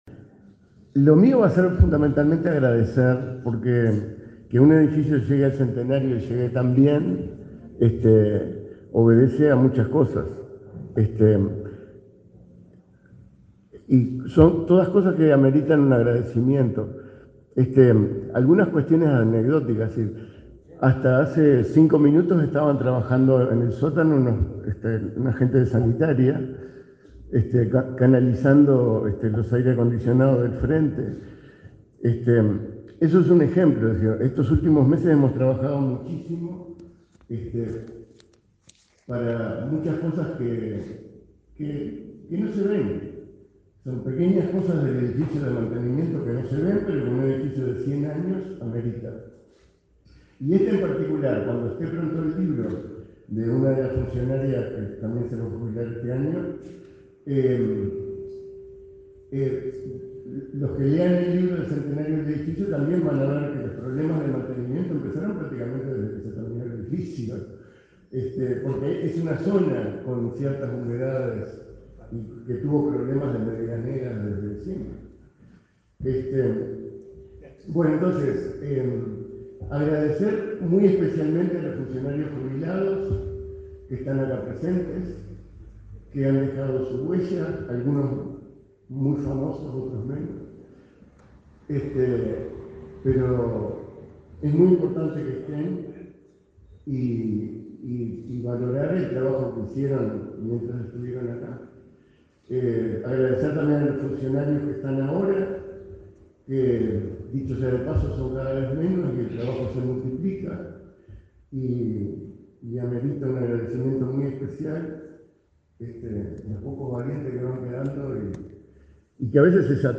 Palabras de autoridades en lanzamiento del Correo Uruguayo
Palabras de autoridades en lanzamiento del Correo Uruguayo 26/09/2022 Compartir Facebook X Copiar enlace WhatsApp LinkedIn Este lunes 26, el director del Archivo General de la Nación, Alberto Umpiérrez; el presidente del Correo, Rafael Navarrine, y la subsecretaria de Educación y Cultura, Ana Ribeiro, participaron en la presentación del sello conmemorativo de los 100 años de la sede del referido archivo.